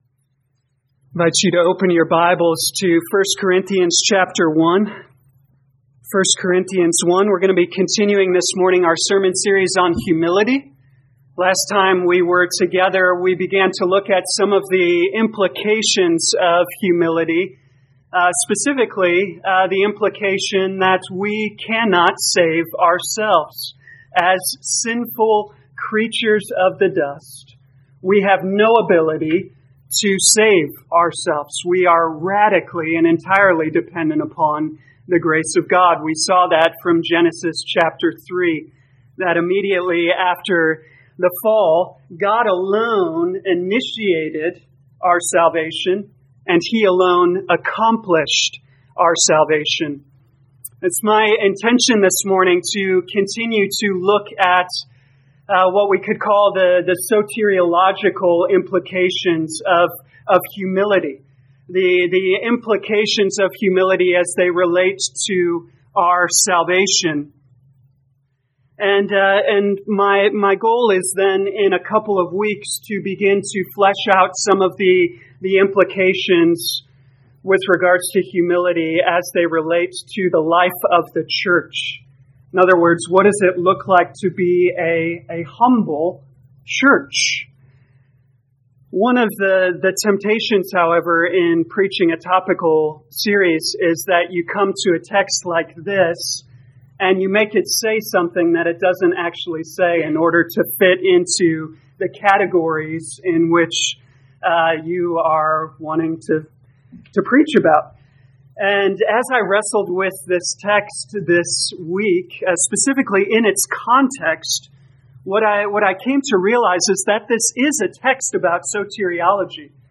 2020 1 Corinthians Humility Morning Service Download